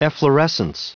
Prononciation du mot efflorescence en anglais (fichier audio)
Prononciation du mot : efflorescence